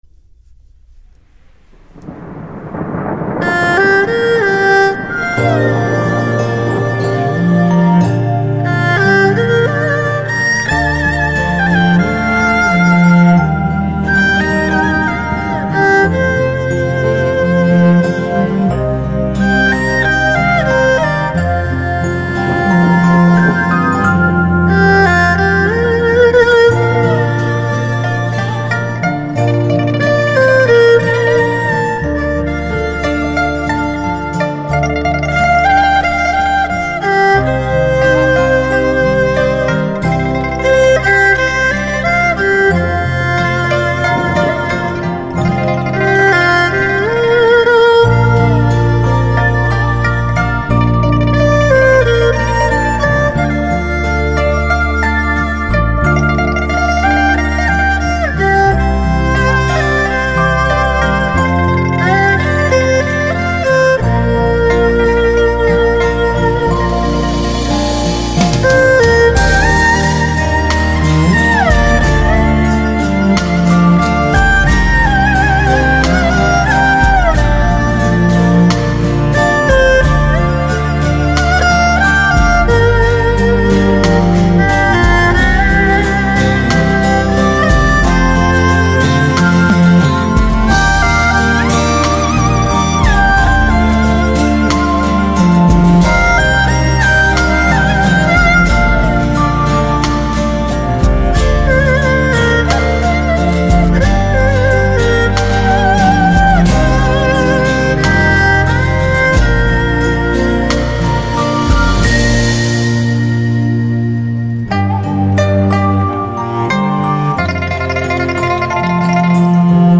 东方音乐